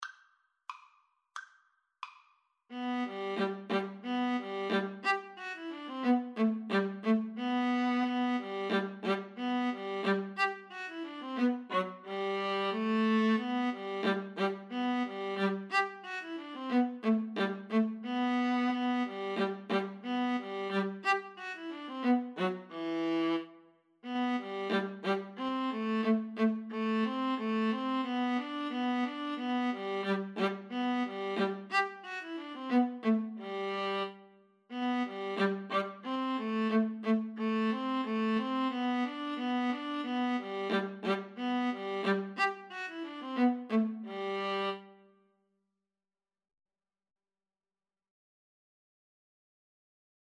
Allegro risoluto = c.90 (View more music marked Allegro)
2/4 (View more 2/4 Music)
Classical (View more Classical Viola Duet Music)